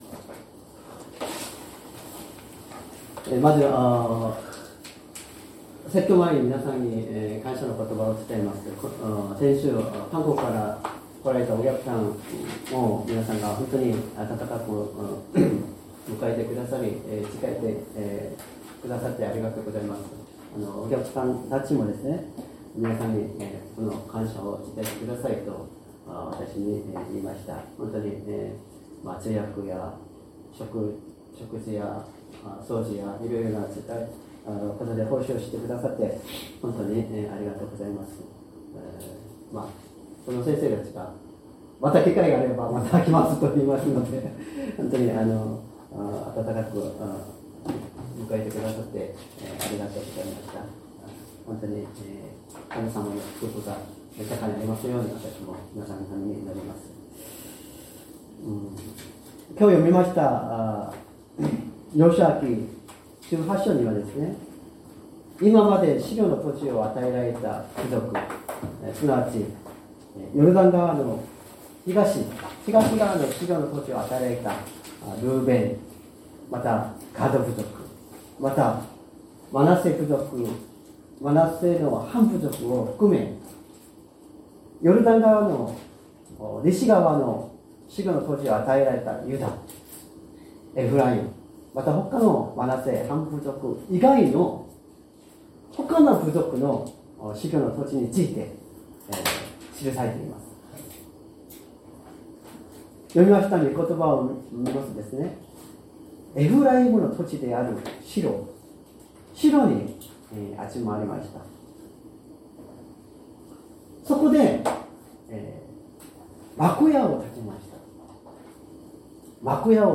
善通寺教会。説教アーカイブ 2025年02月16日朝の礼拝「土地の記録を作り、戻ってきなさい。」
音声ファイル 礼拝説教を録音した音声ファイルを公開しています。